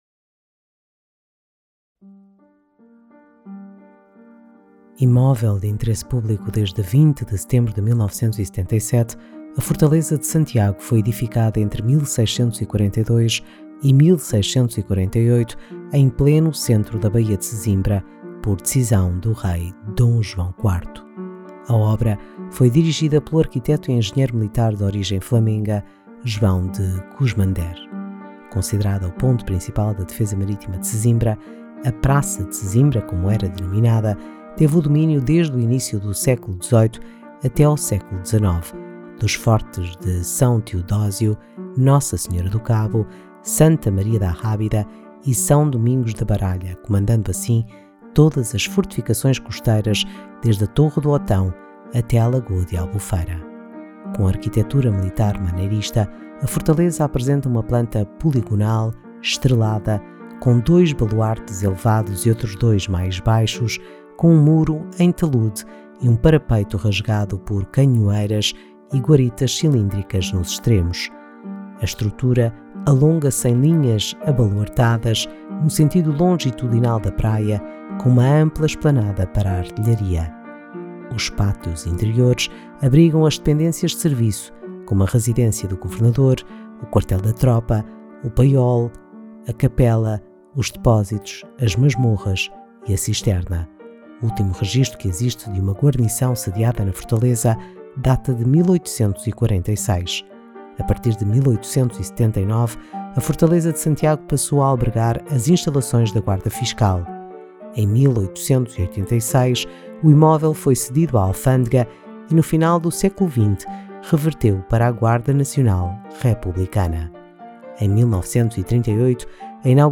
Audioguia da Fortaleza de Santiago
Audioguia sobre a Fortaleza de Santiago.
Audioguia_Fortaleza_FINAL.mp3